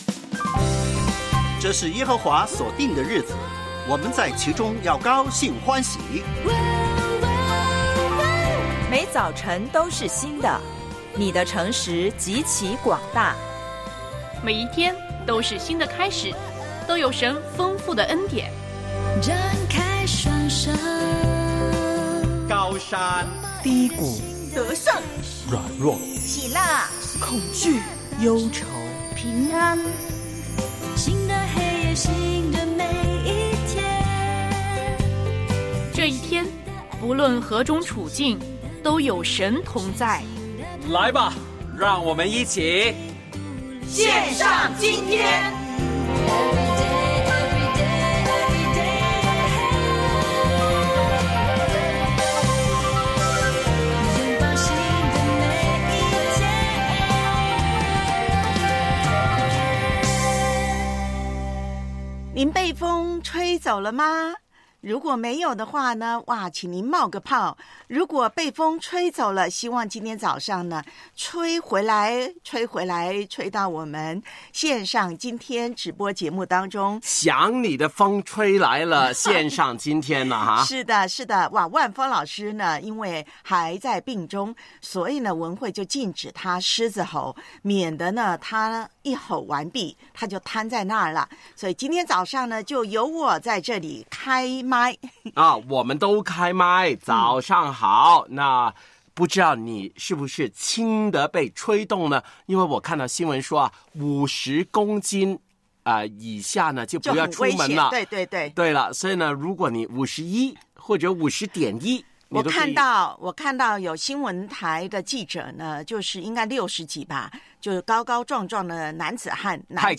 教会年历灵修：约翰福音12:1-8；复活节广播剧（1）耶稣被定罪；我爱背金句：撒母耳记上7:12